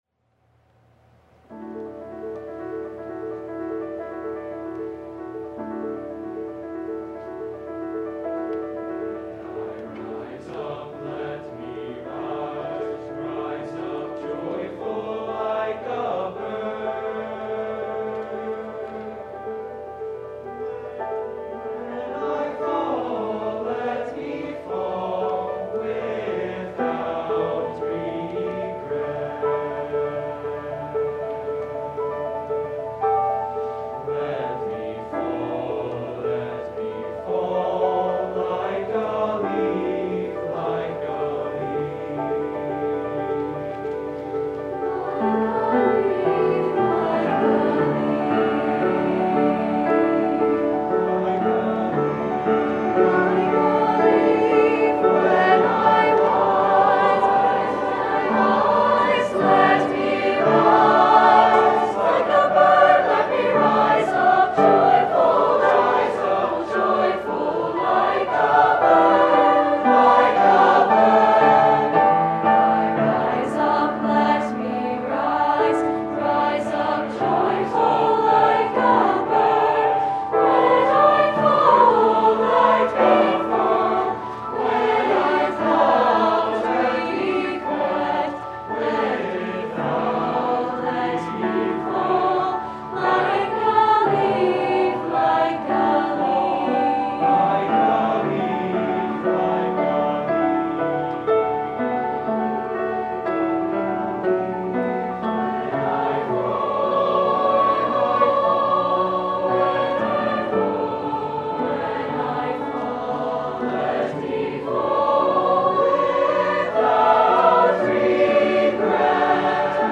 A rhapsodic setting
SATB, piano